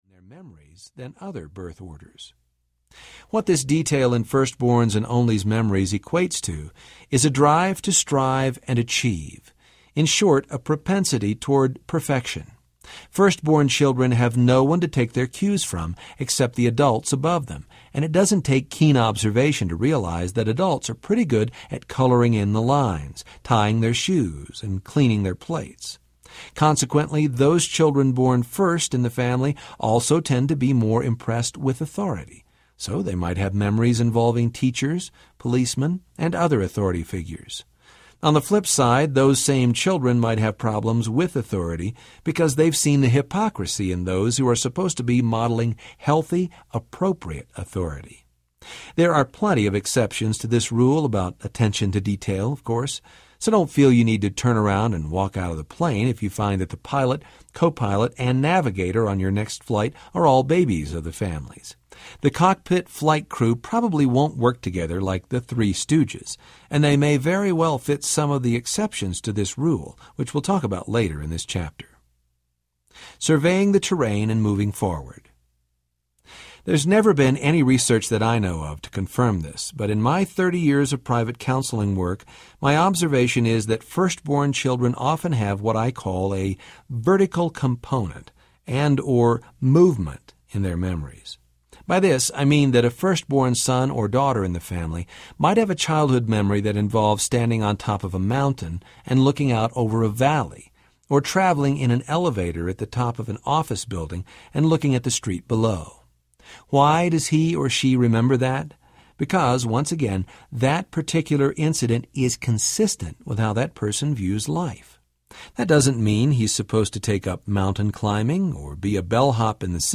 What Your Childhood Memories Say About You Audiobook
7.5 Hrs. – Unabridged